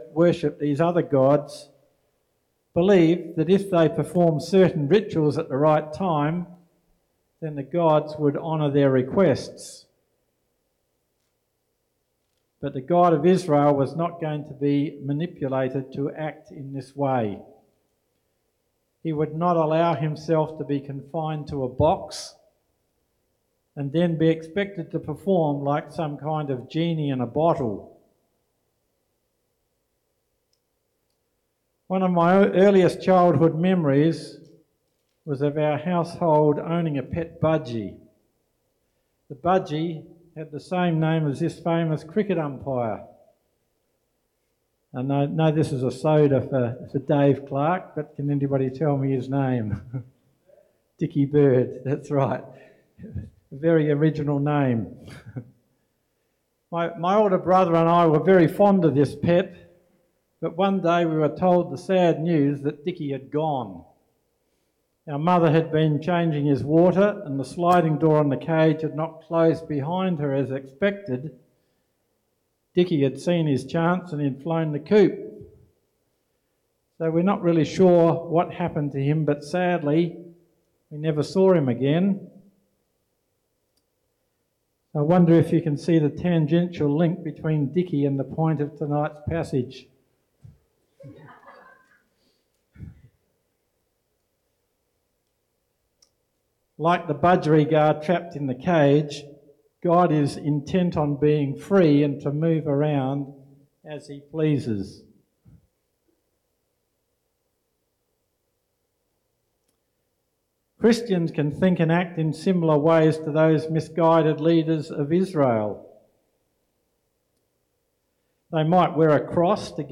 (1 Samuel 4:1-11) NOTE: We apologise that the beginning of this message was lost due to audio issues. The people of Israel sought to employ the ark of the covenant, representing the presence of God, as a tool for military victory against the Philistines.